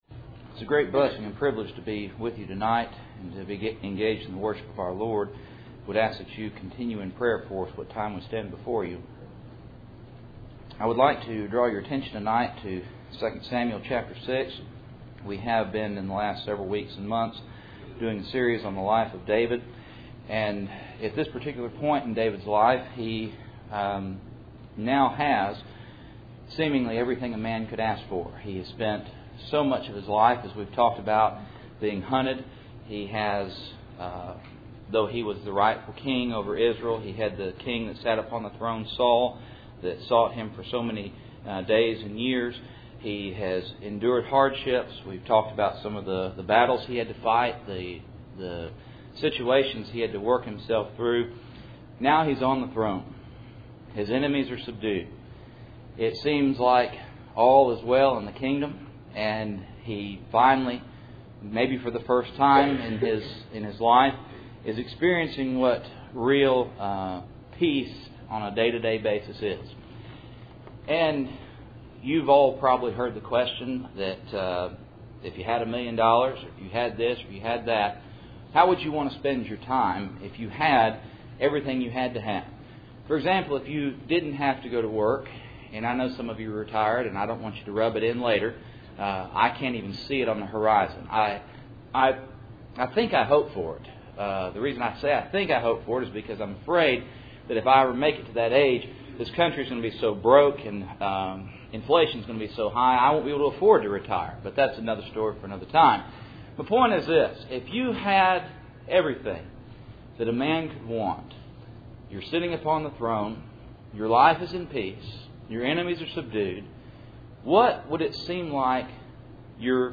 Cool Springs PBC Sunday Evening